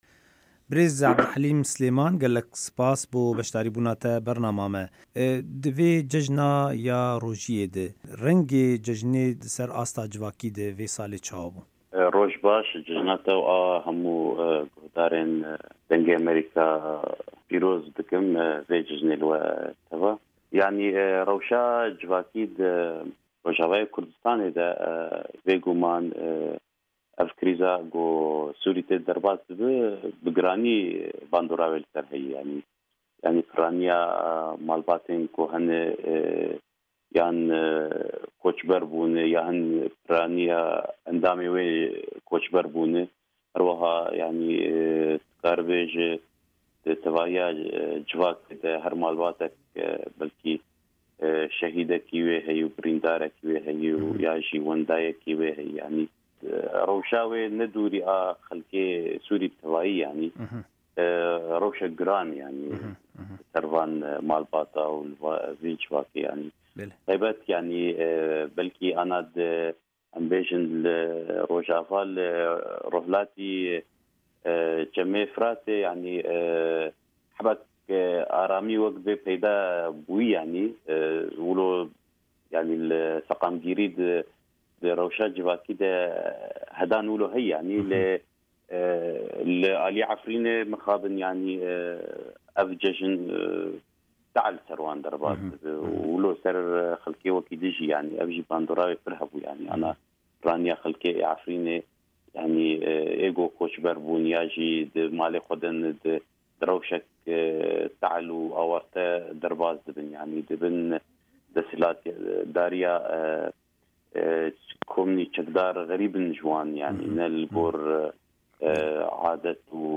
Hevpeyvîna